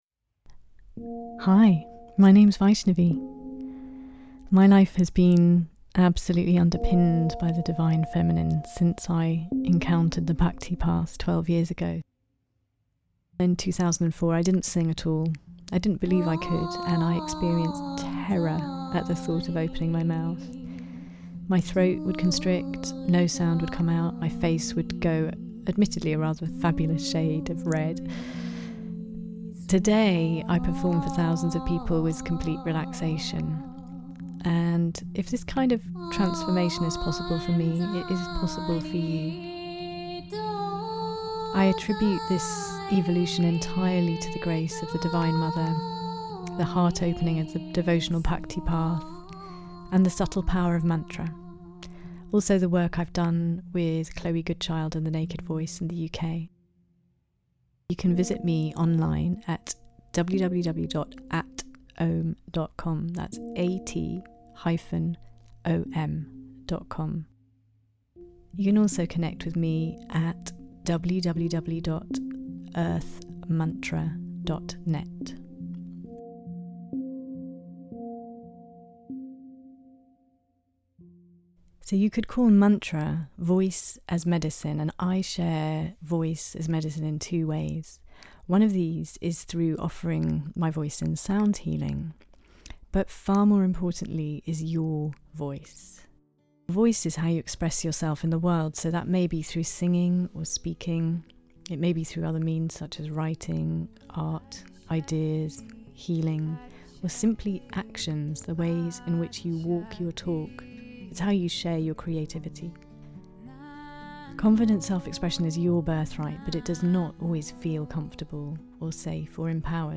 Mantra Medicine Guided Sounding Journey – this 26-minute sound experience supports you to free YOUR voice.
The Journey: starts with a deep AUM into the root of your being and then takes and uplifting journey back up through the chakras with our Adi Shakti mantra.